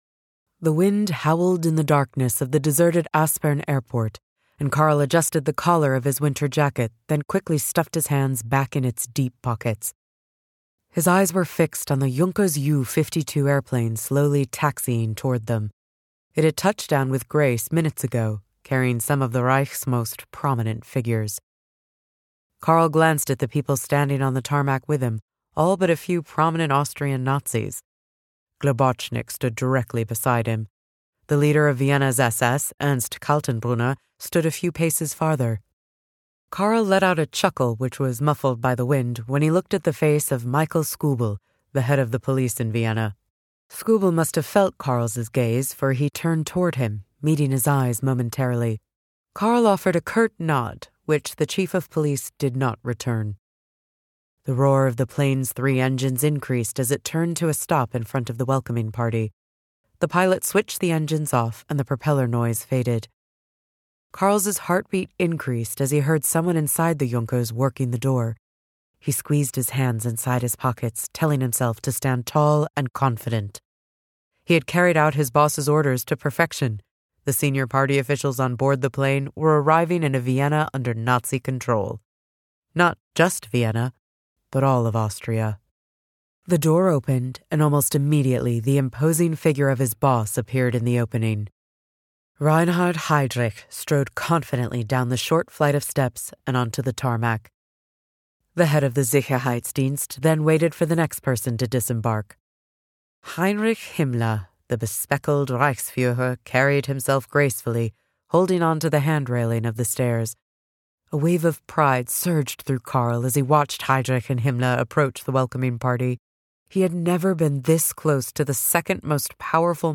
The Eagle's Shadow audiobook - Michael Reit - Buy direct from author!